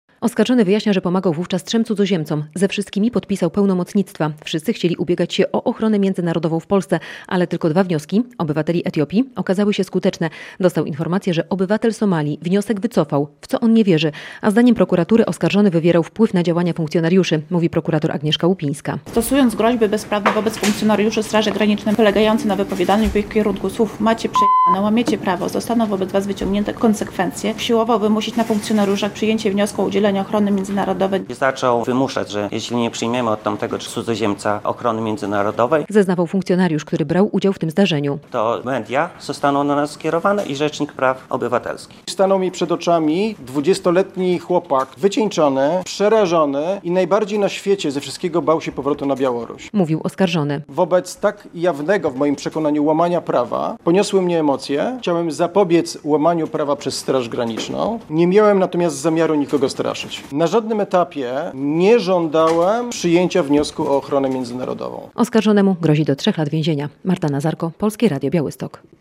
Proces wolontariusza działającego przy polsko-białoruskiej granicy - relacja